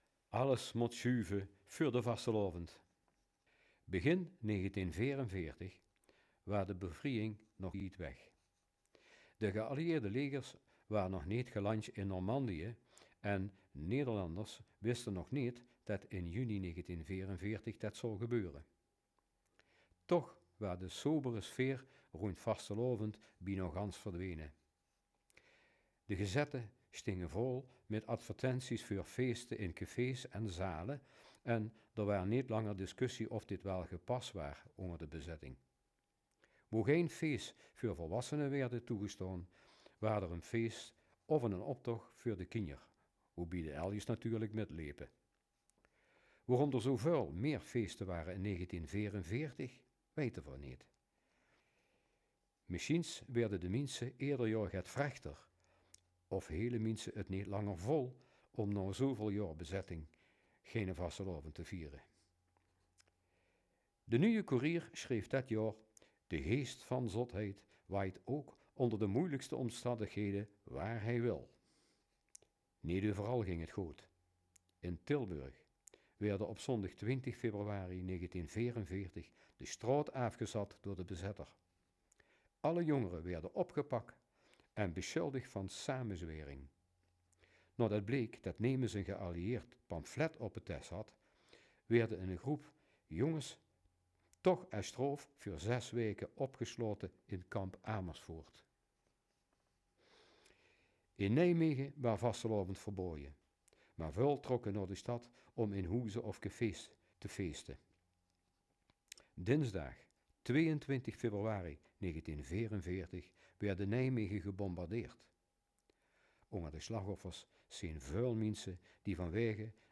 Luister in het Roermonds:
roermond-audiotour-8.m4a